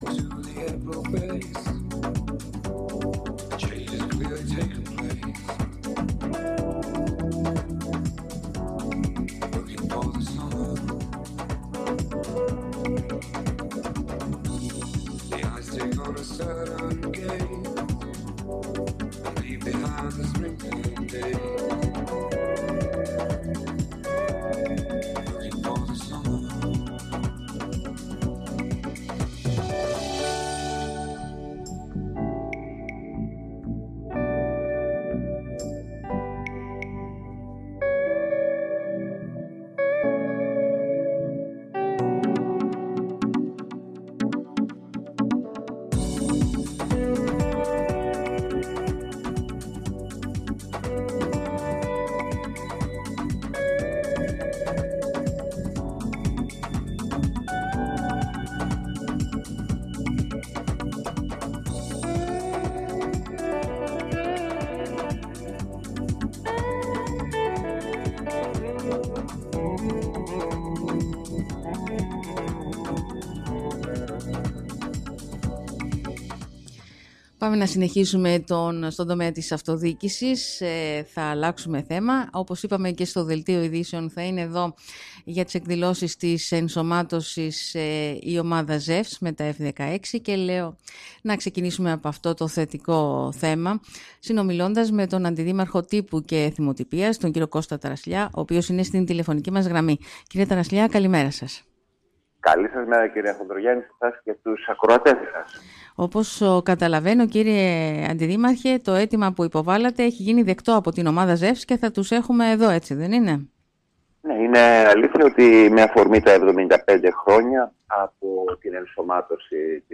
Αυτό υπογράμμισε μιλώντας στον Sky με αφορμή την  συζήτηση που ευρύτερα γίνεται  με φόντο τις εκλογές της αυτοδιοίκησης ο αντιδήμαρχος Τύπου και Εθιμοτυπίας κ. Κώστας Ταρασλιάς.